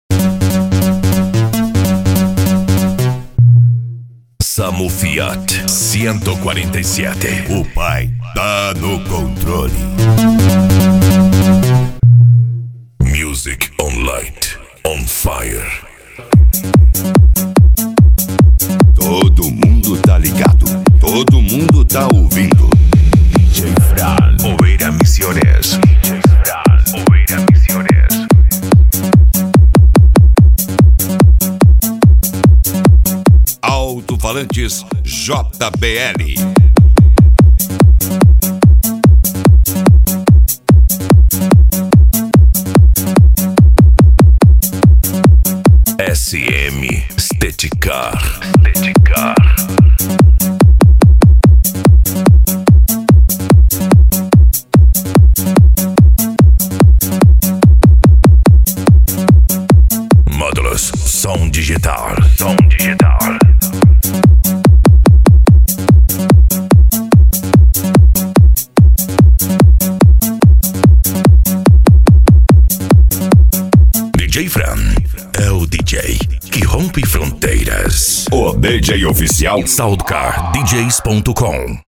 PANCADÃO
Remix